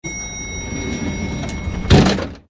elevator_door_close.ogg